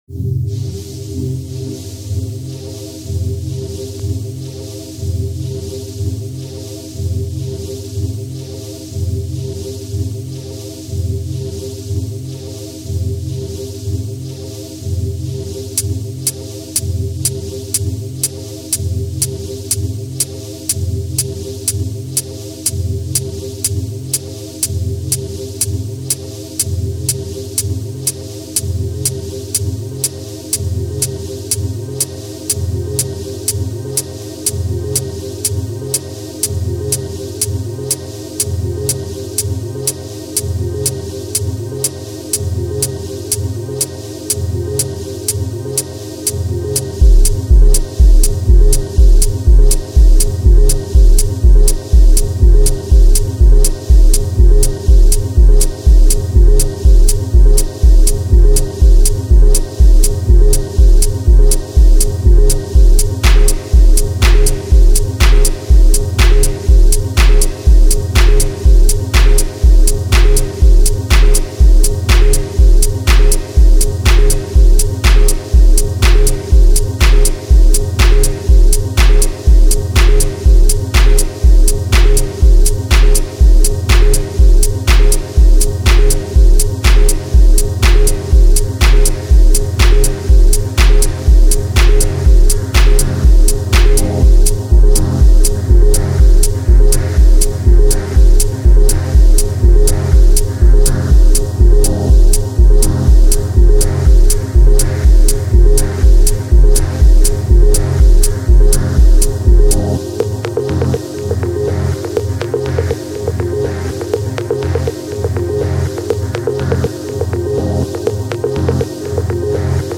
Another little slice of textural, Basic Channel-style techno. This song is about all those people that have dropped into my life and made a huge impact.